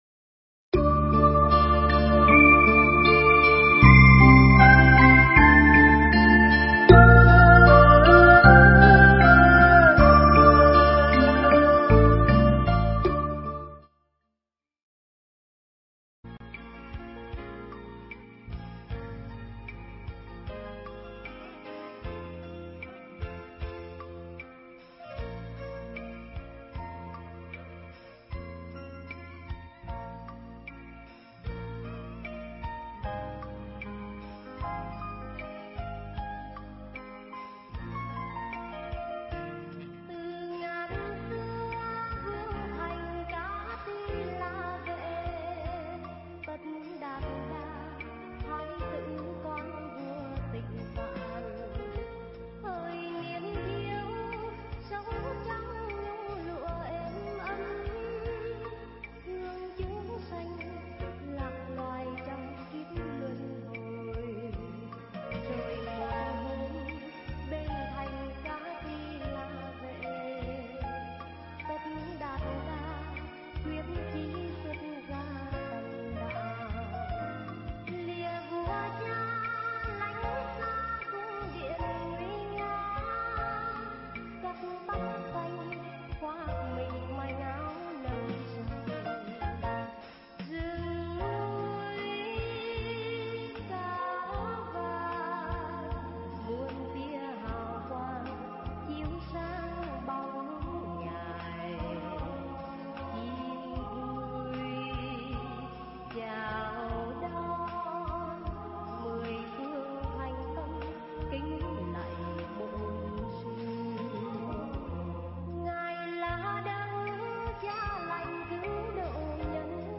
Nghe Mp3 thuyết pháp Nhân Duyên Niệm Phật
Mp3 Pháp thoại Nhân Duyên Niệm Phật